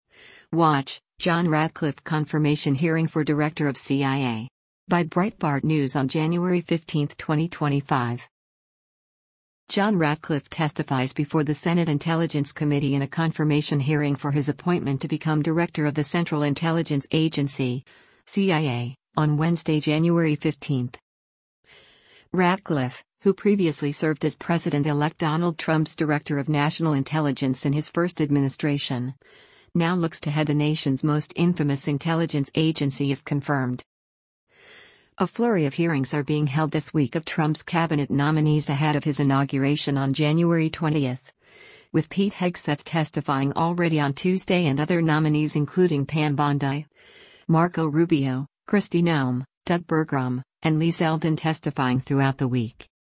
John Ratcliffe testifies before the Senate Intelligence Committee in a confirmation hearing for his appointment to become director of the Central Intelligence Agency (CIA) on Wednesday, January 15.